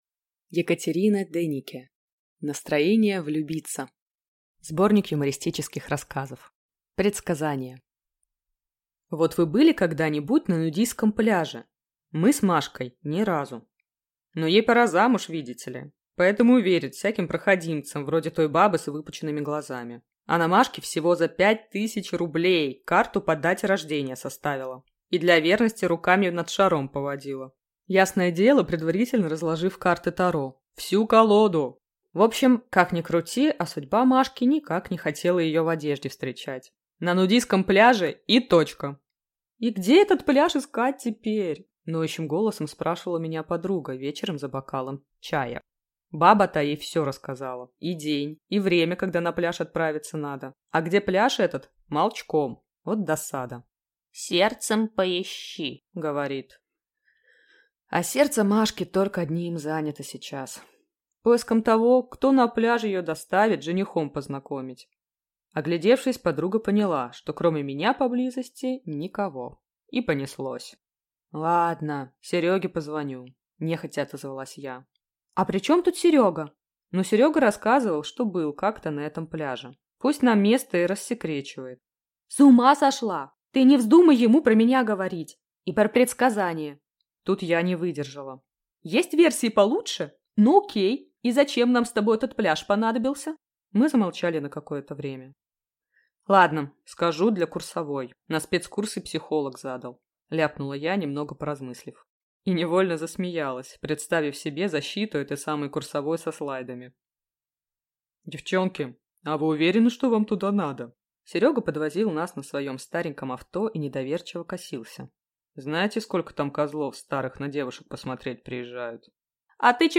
Аудиокнига Настроение влюбиться. Сборник юмористических рассказов | Библиотека аудиокниг